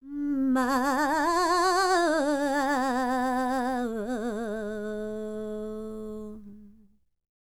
QAWALLI 05.wav